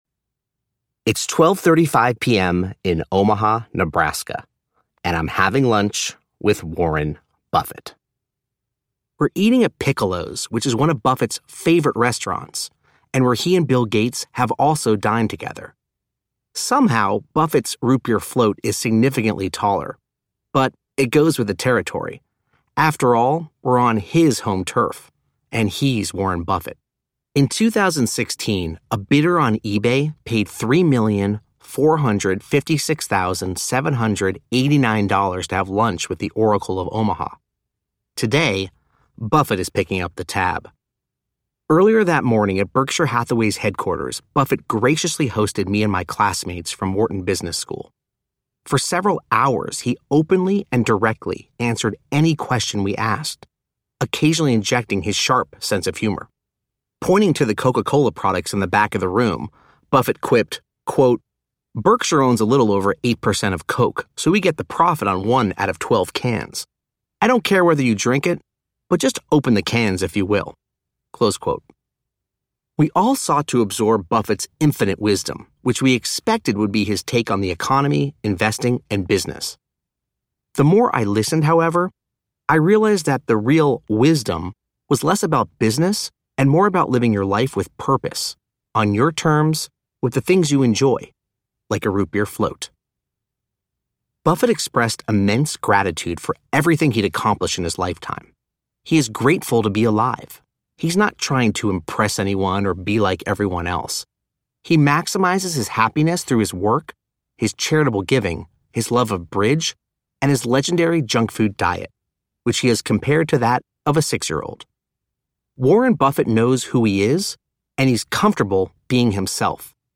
The Lemonade Life Audiobook
Narrator